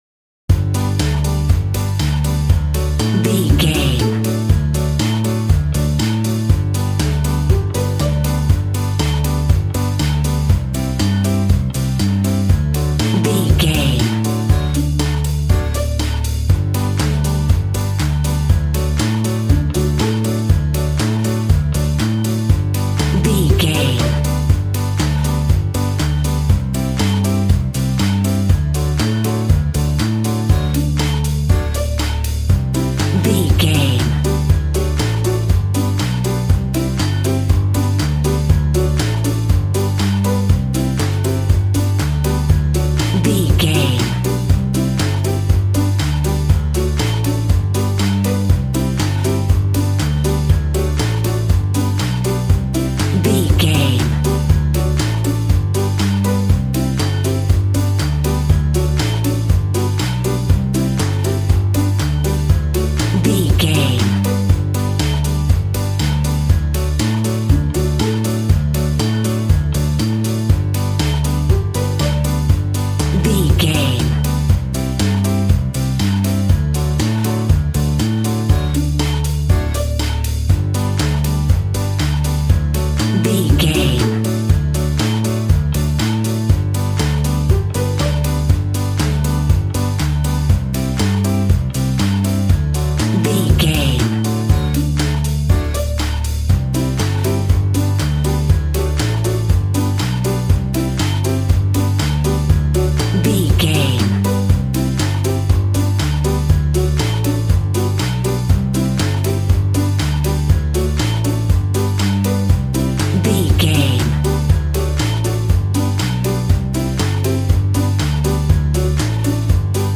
A great piece of royalty free music
Uplifting
Ionian/Major
Fast
instrumentals
fun
childlike
cute
happy
kids piano